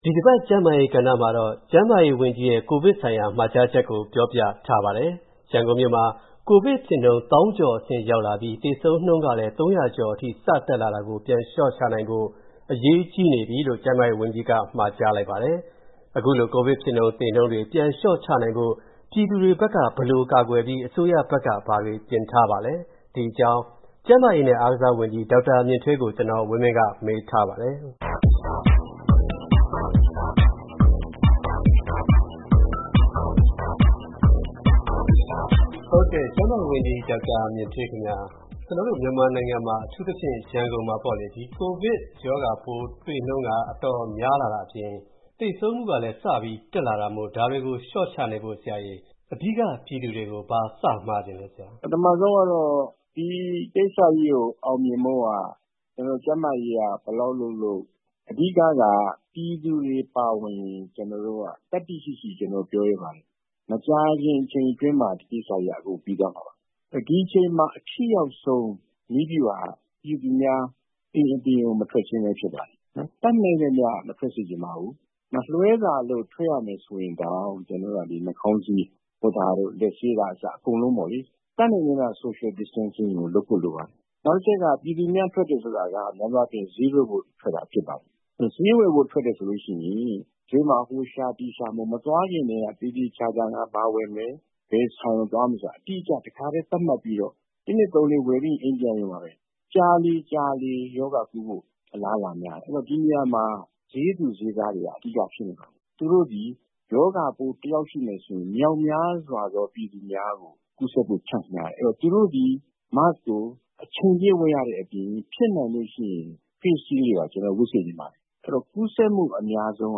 ကျန်းမာရေးနဲ့အားကစားဝန်ကြီး ဒေါက်တာမြင့်ထွေးကို ဆက်သွယ်မေးမြန်းထားပါတယ်။